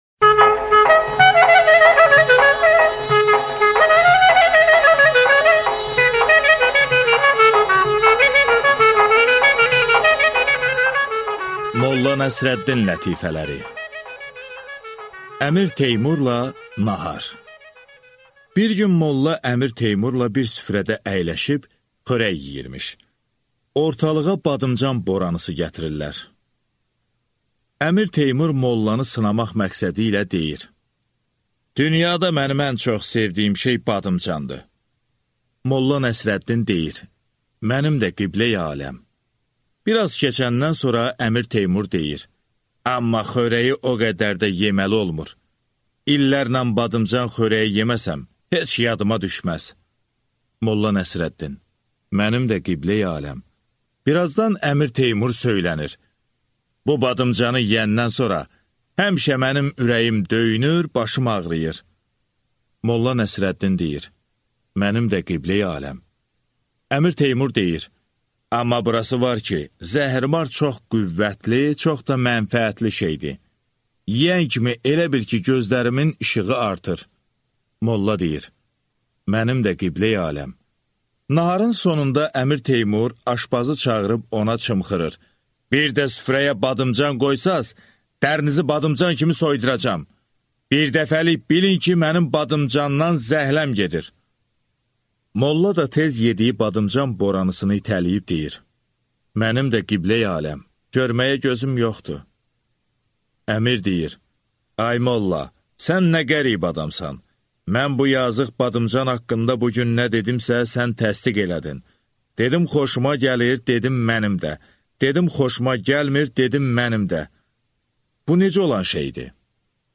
Аудиокнига Molla Nəsrəddinin lətifələri | Библиотека аудиокниг